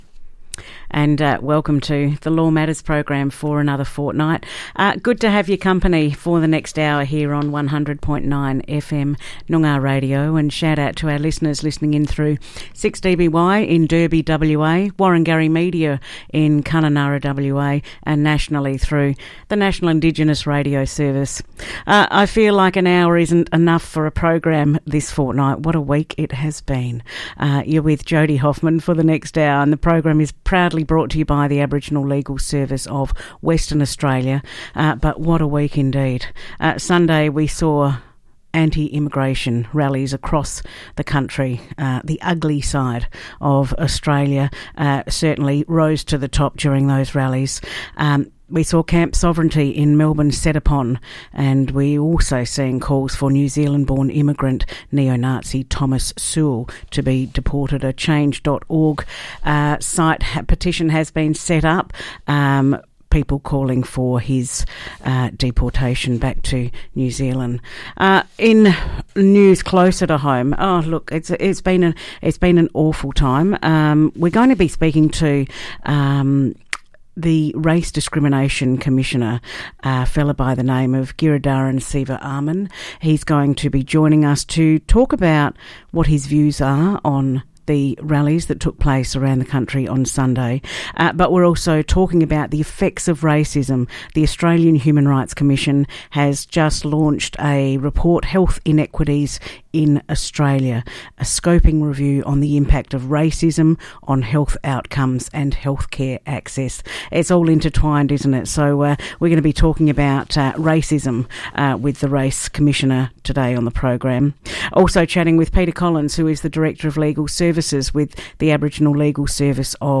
Radio Programs
Australian Human Rights Commission Race Discrimination Commissioner Giridharan Sivaraman shares his views on the anti-immigration rallies that took place last weekend and speaks about the AHRC report released this week: ‘Health Inequities in Australia: A Scoping Review on the Impact of Racism on Indigenous and Other Negatively Racialised Communities’ Health Outcomes and Healthcare Access’.